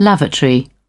Transcription and pronunciation of the word "lavatory" in British and American variants.